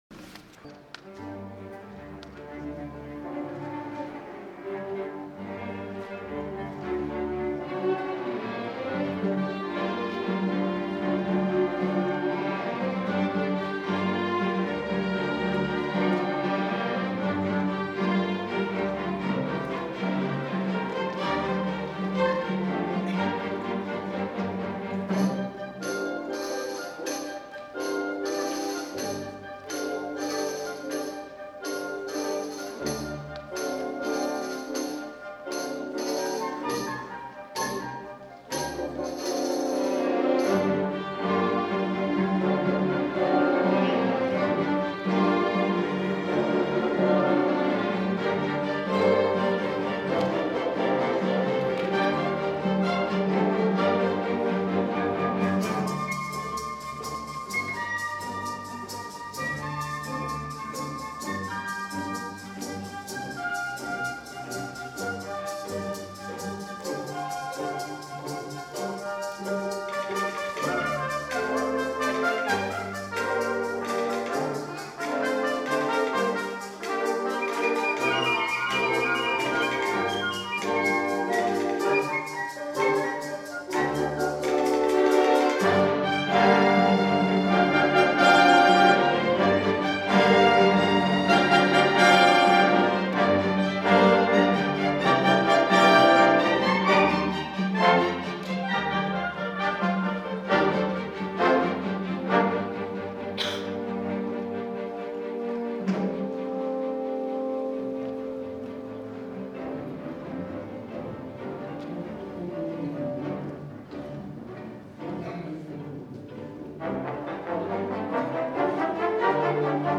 for Orchestra (2003)
For this is an especially slippery piece. Strings slide.
At the end, all slide together.